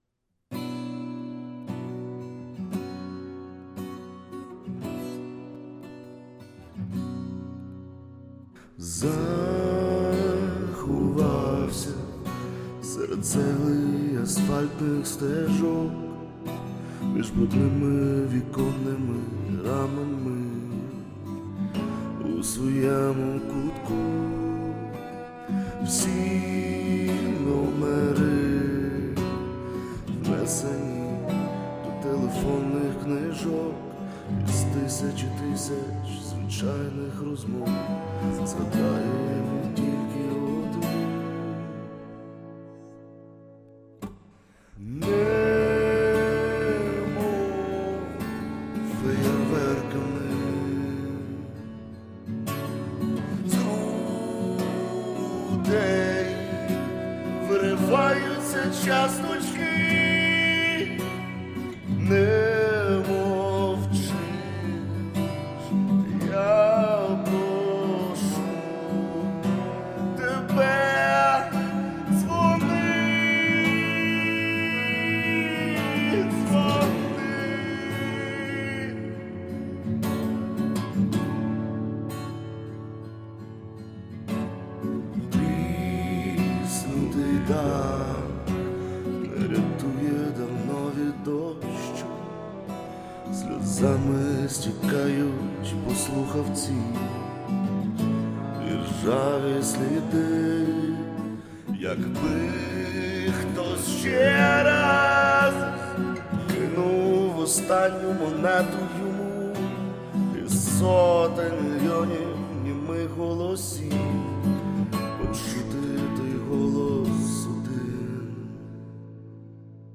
ТИП: Пісня
СТИЛЬОВІ ЖАНРИ: Ліричний
ВИД ТВОРУ: Авторська пісня